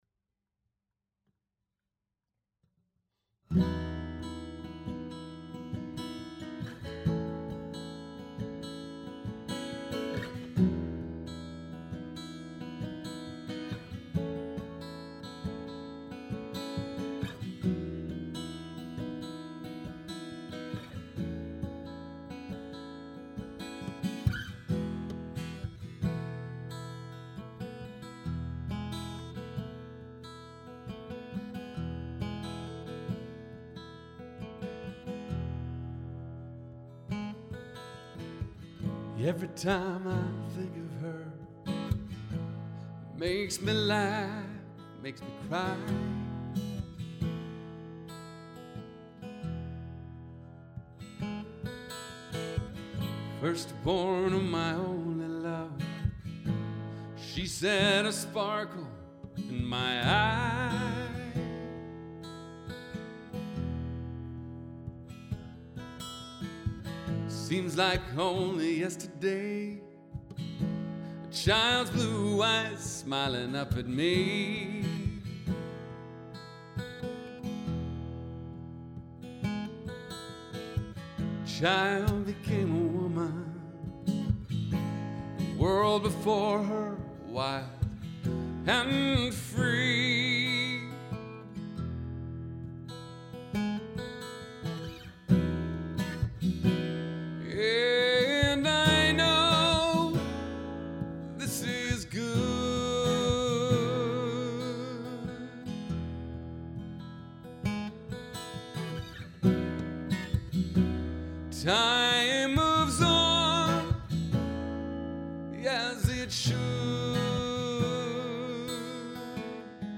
broken-hearted-joy-3-rough-mix.mp3